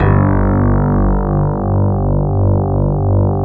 12 SJ BASS-R.wav